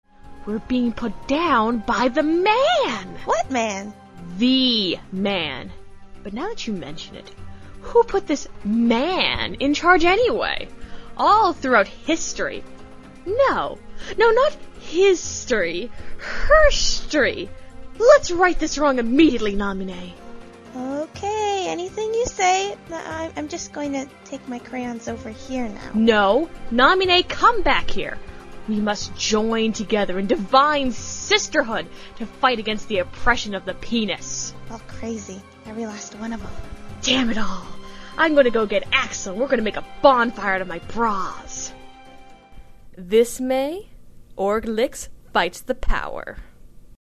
We are an Organization-centric audio drama group who love producing crackish comedies for general consumption by other KH fans. This May, we are going to start releasing our work on a wide scale and just to whet your pallet, we have a few samples in the form of these swanky commercials.